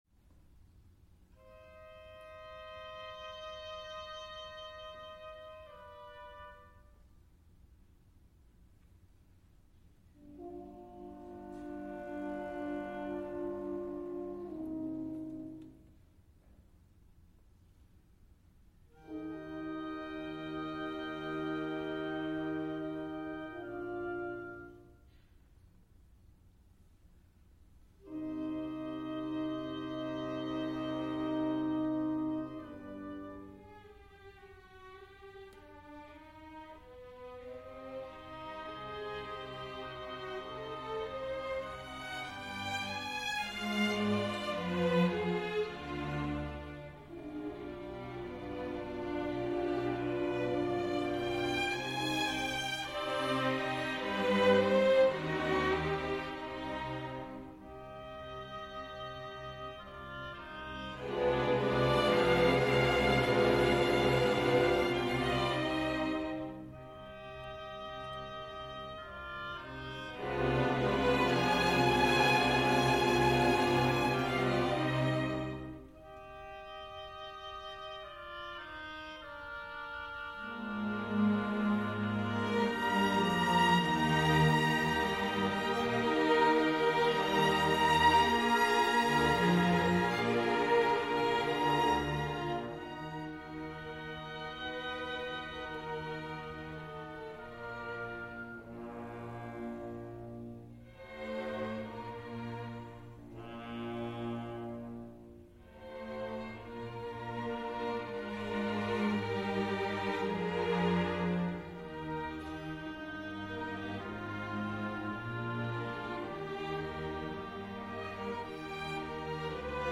Funeral march